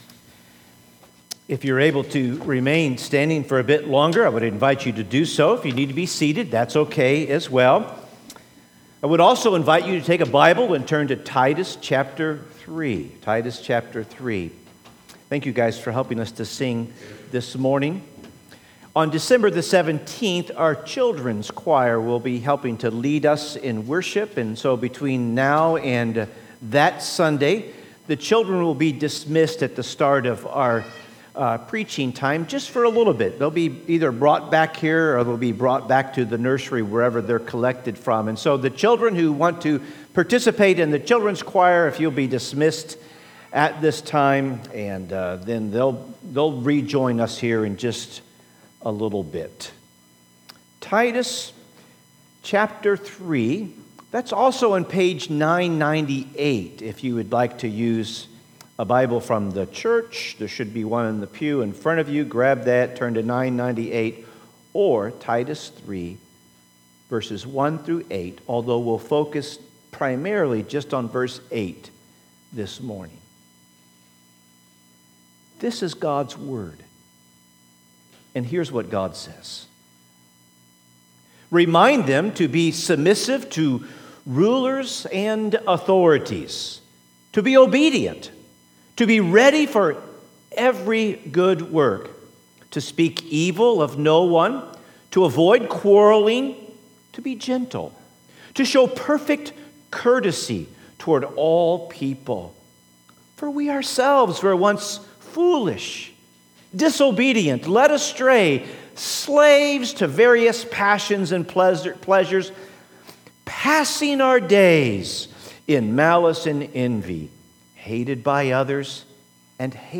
Sermons | First Baptist Church St Peters